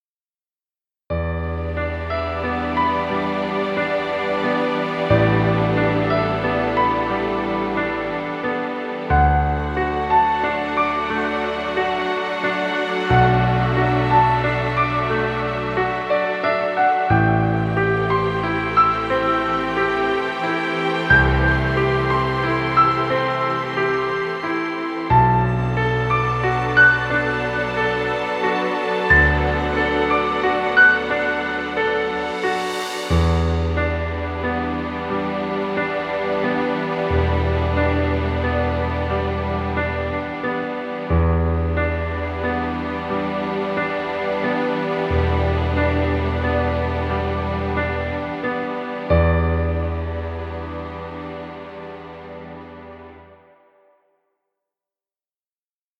Romantic soft music.
Stock Music.